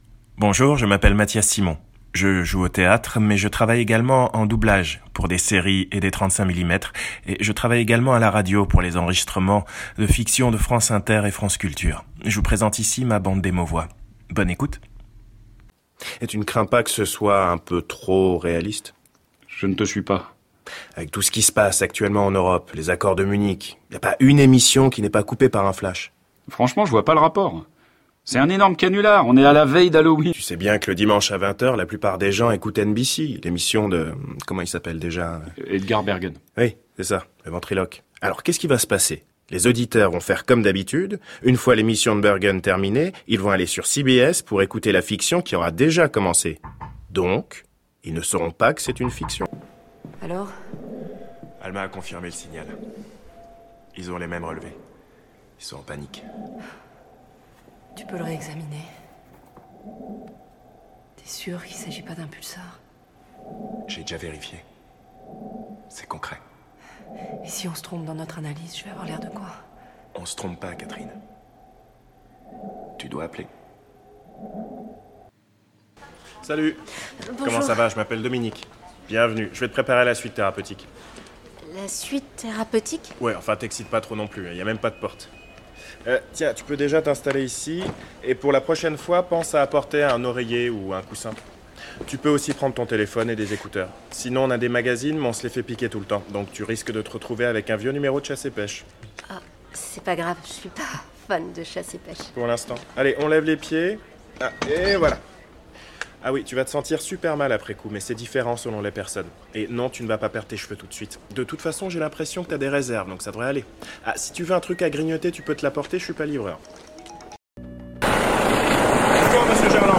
Voix off
bande démo voix
30 - 55 ans